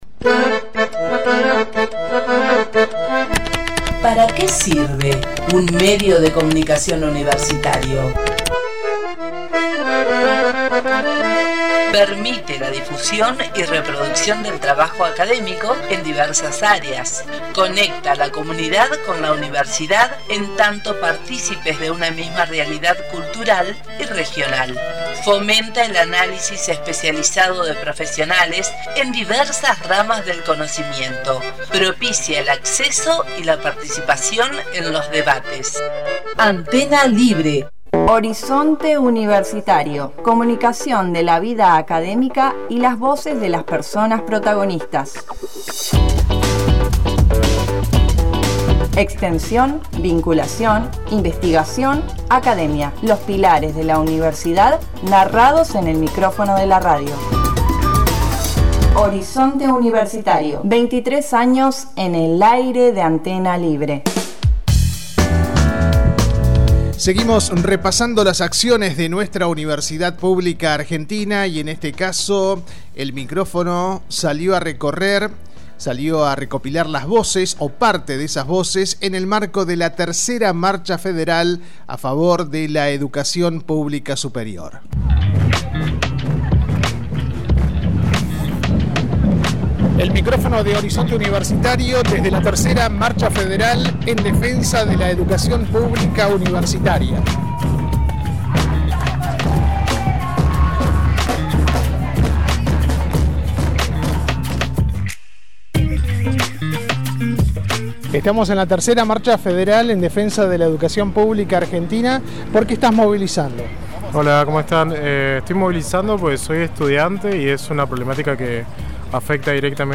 Desde Horizonte Universitario, recogimos los testimonios de quienes alzaron su voz para defender el futuro de la Universidad Pública, un pilar fundamental para el desarrollo de nuestra sociedad.